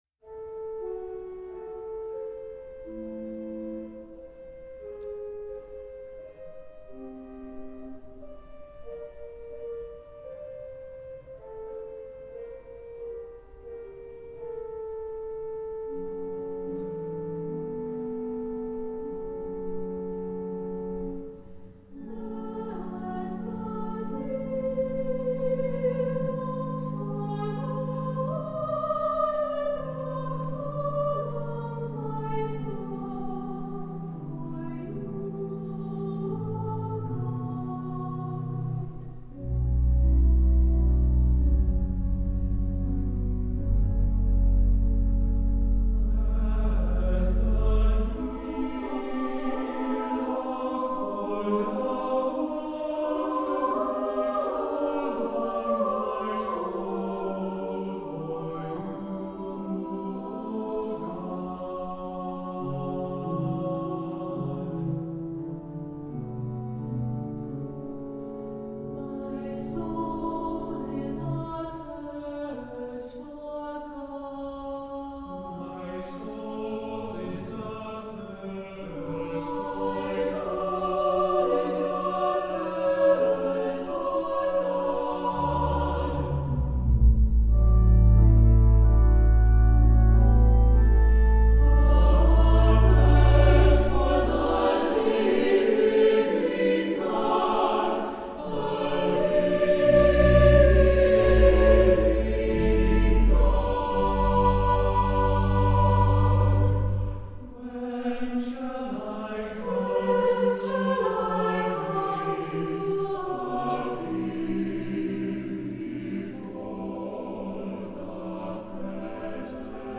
Voicing: SATB and Organ